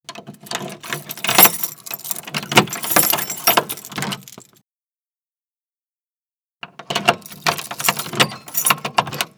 KFkmfLfaX0L_OBJKey-Serrure-ID-0191-LS.wav